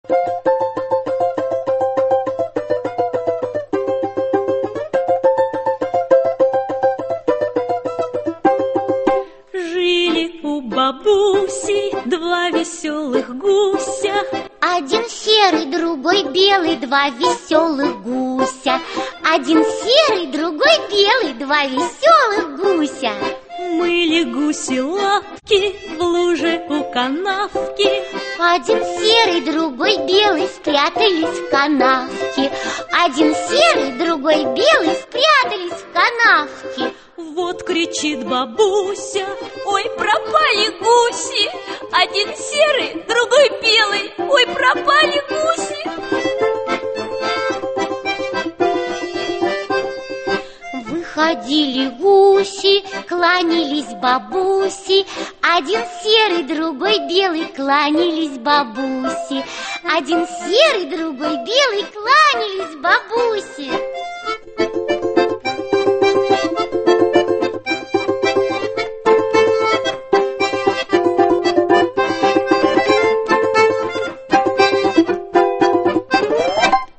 Аудиокнига Русские народные песни | Библиотека аудиокниг